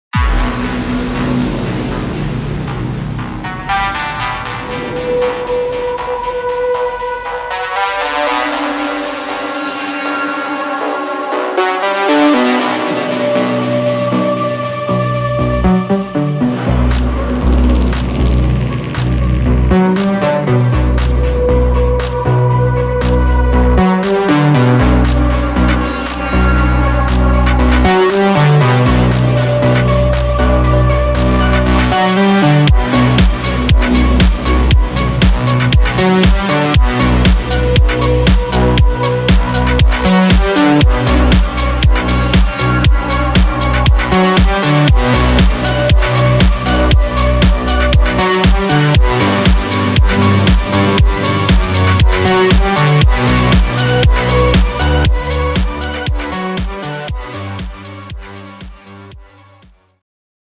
Techno
• 220 Analog Sweeps (Sorted by Key)
• 24 Bonus Atmospheres